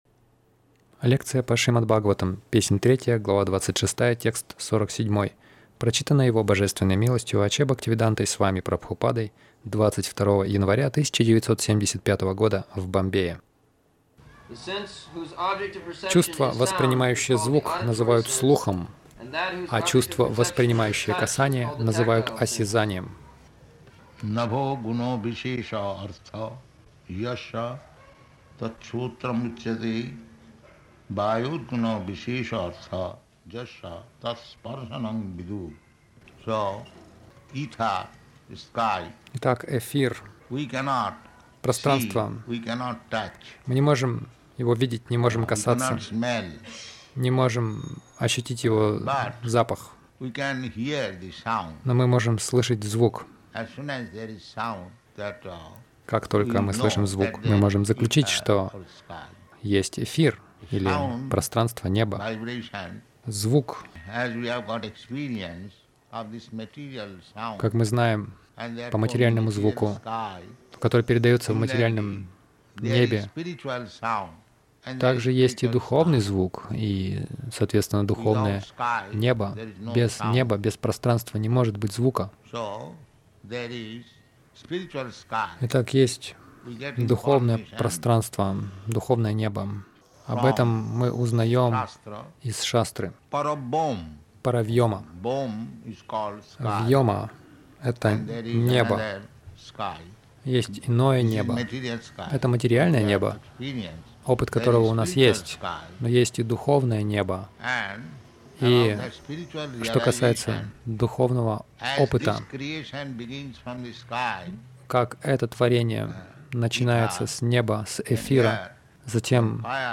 Милость Прабхупады Аудиолекции и книги 22.01.1975 Шримад Бхагаватам | Бомбей ШБ 03.26.47 — Материальный и духовный звук Загрузка...